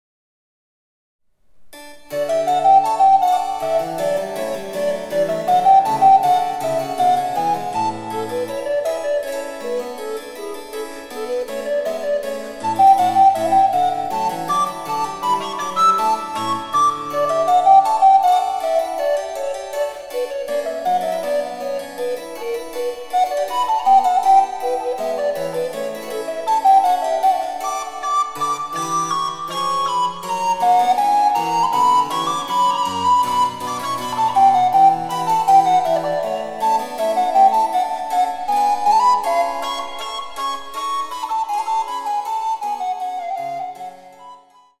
第１楽章はアレグロ（快活に）と指定された４分の４拍子のプレリュード（前奏曲）です。対位法的な味わいが濃厚ですが、それでいて歌謡性がゆたかで親しみやすく、リズムの扱いも気が利いている、極上の音楽です。
■リコーダーによる演奏